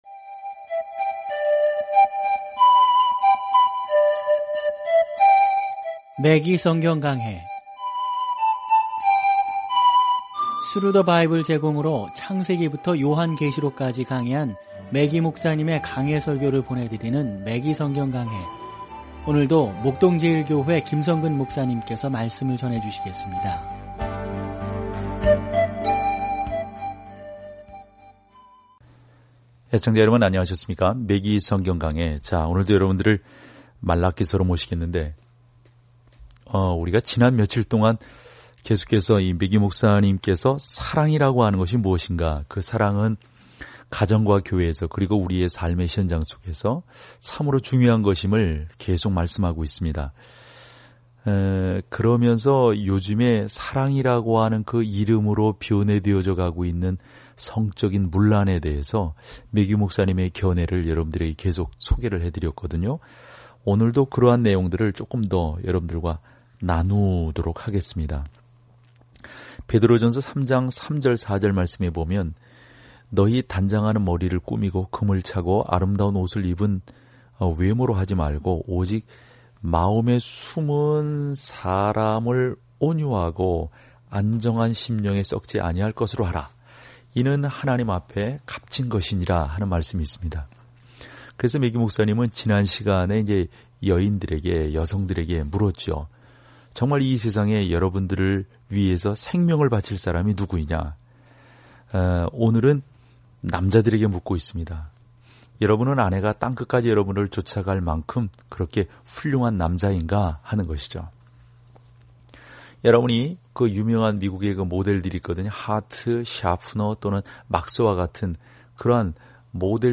오디오 공부를 듣고 하나님의 말씀에서 선택한 구절을 읽으면서 매일 말라기를 여행하세요.